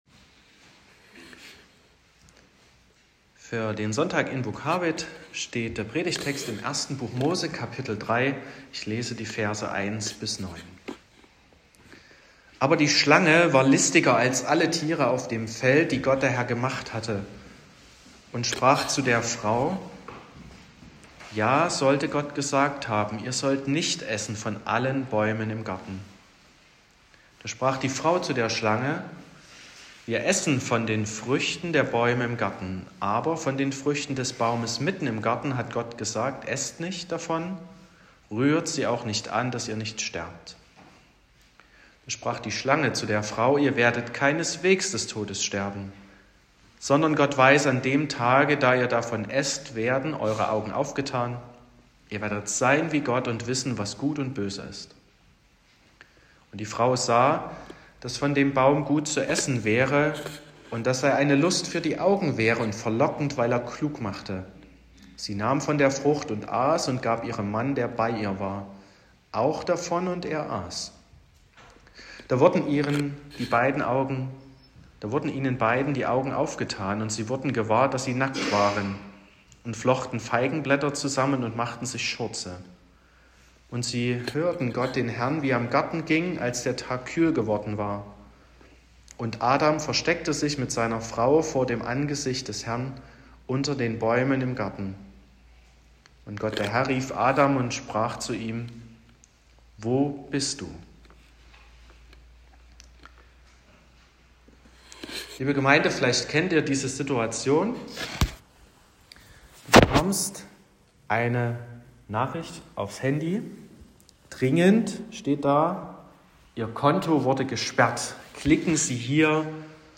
22.02.2026 – Gottesdienst
Predigt (Audio): 2026-02-22_Getaeuscht__gefallen_und_gesucht.m4a (9,7 MB)